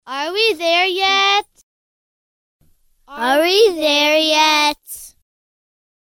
KIDS – ARE WE THERE YET
KIDS-ARE-WE-THERE-YET.mp3